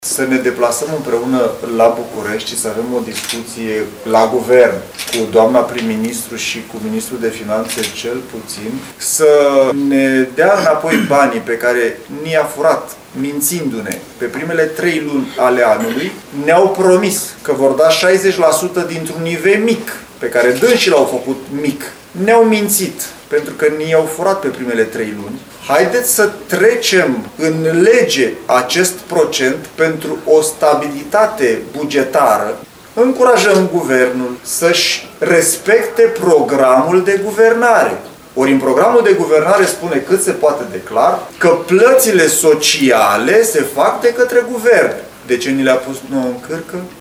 Președintele Asociaţiei Municipiilor din România ROBERT NEGOIȚĂ – primarul sectorului 3 al Capitalei, a menționat că proiectele propuse sunt esențiale pentru dezvoltarea comunităților.